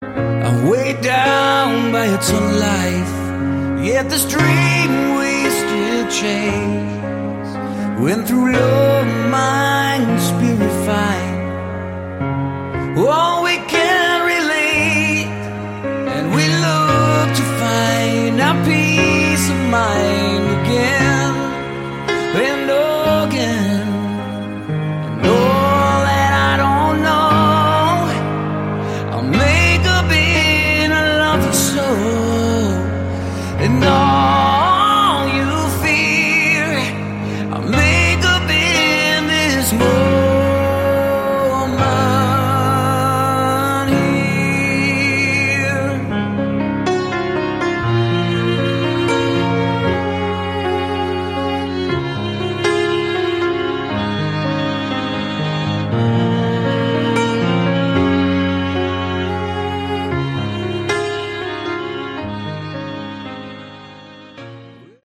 Category: Hard Rock
vocals
guitars
keyboards
bass
drums
The sound quality is top notch.